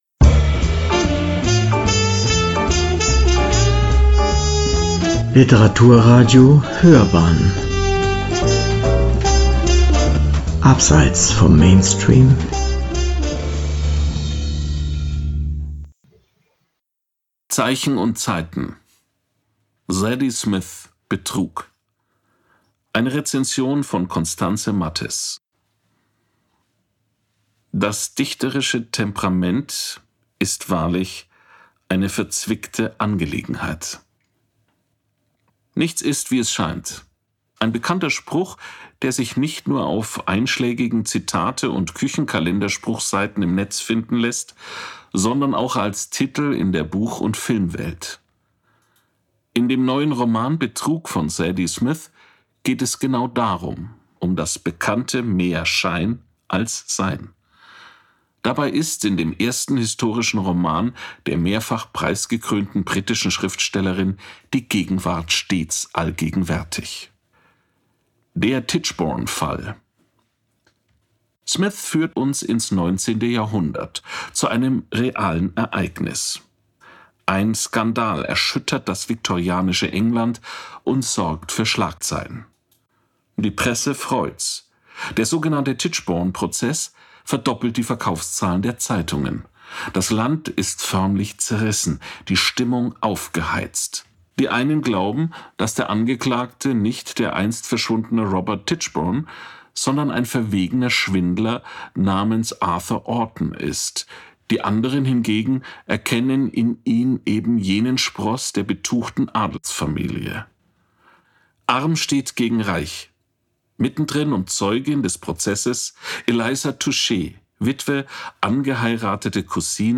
Zeichen & Zeiten: Zadie Smith – „Betrug“ – eine Rezension